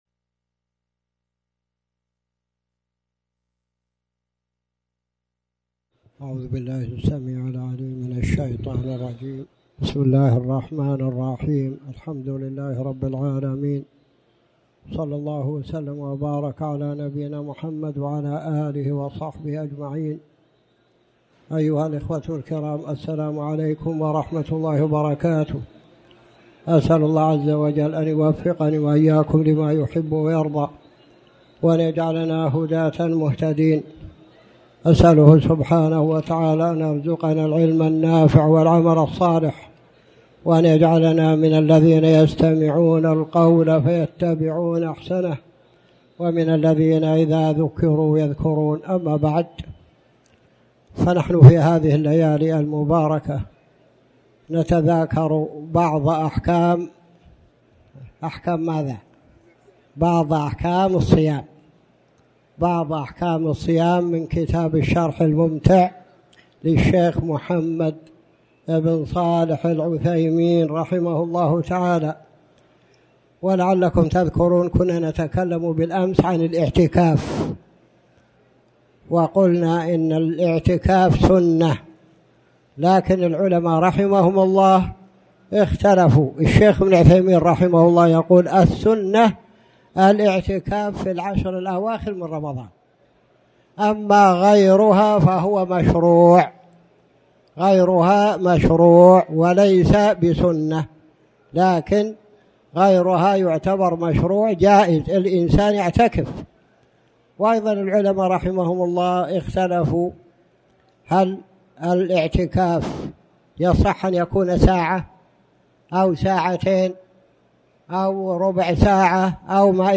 تاريخ النشر ٢٠ شعبان ١٤٣٩ هـ المكان: المسجد الحرام الشيخ